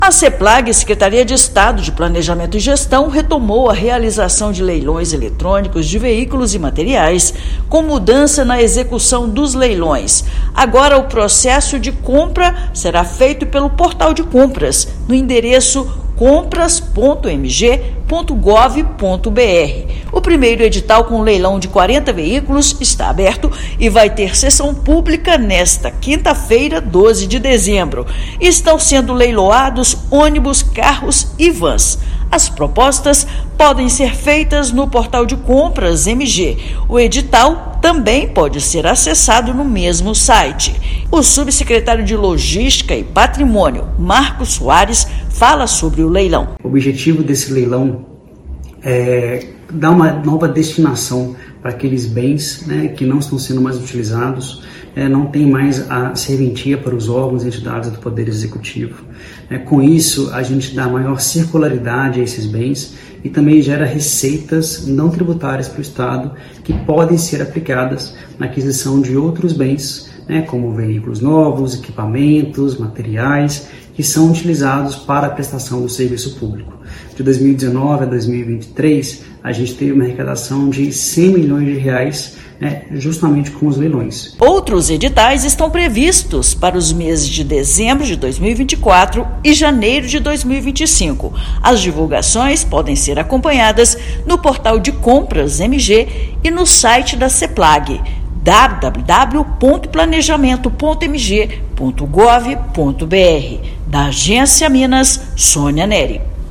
Primeiro edital com 40 itens está aberto e propostas iniciais podem ser feitas até esta quinta-feira (12/12); a mudança para o portal traz melhorias e facilidades para os compradores. Ouça matéria de rádio.